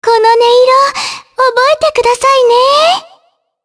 Shea-Vox_Victory_jp.wav